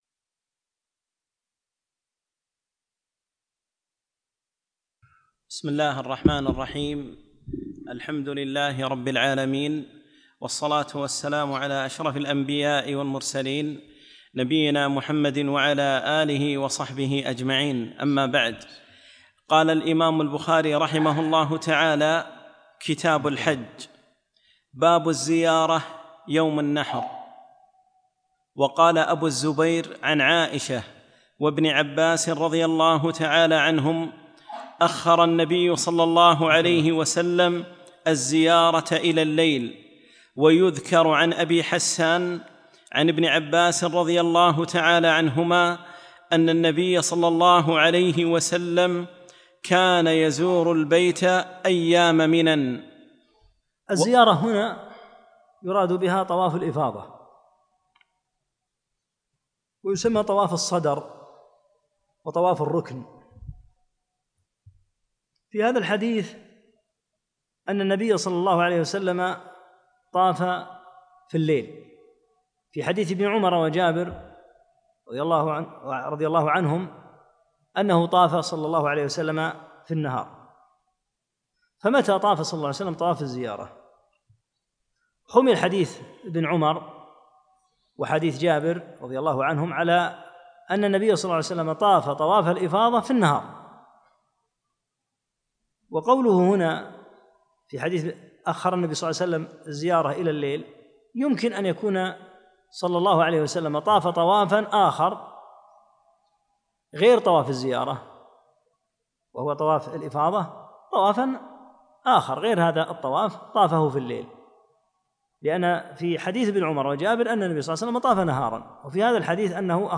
20- الدرس العشرون